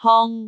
speech
syllable
pronunciation
hong1.wav